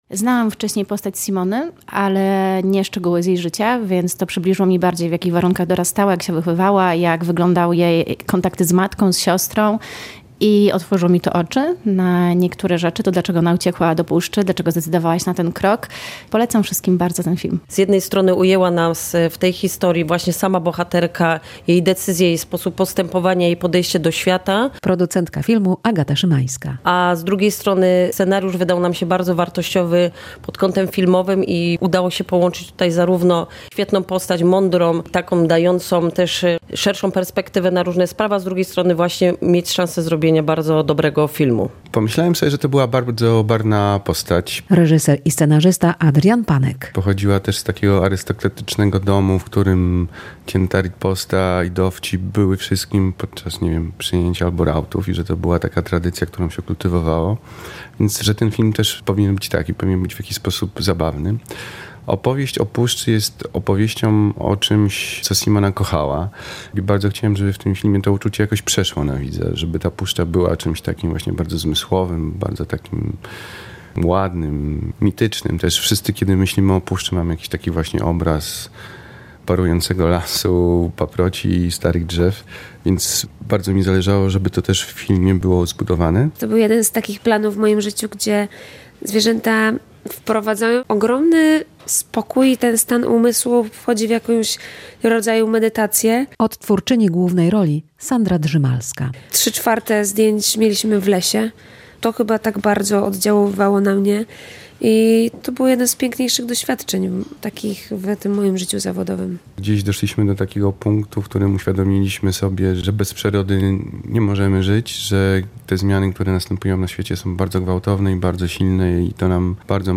Przedpremierowy pokaz filmu fabularnego "Simona Kossak" odbył się w czwartek (21.11) w kinie Helios Alfa w Białymstoku.